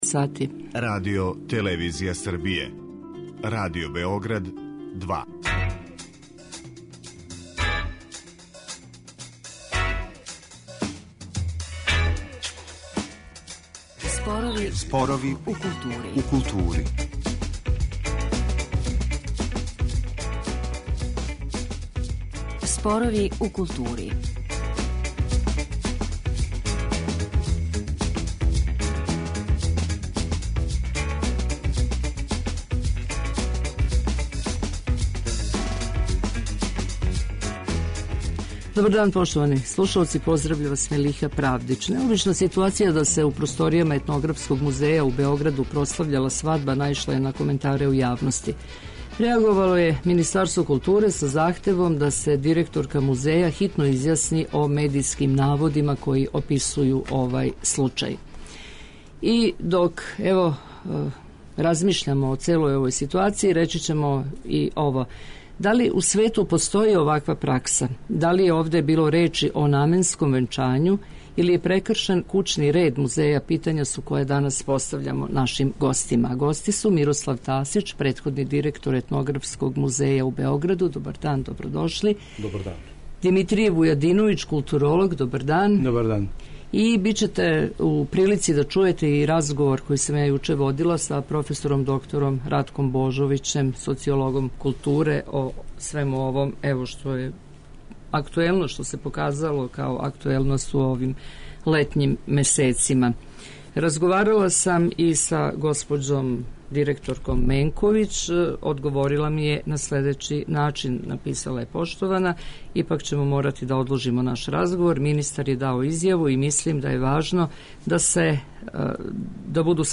Дневни магазин културе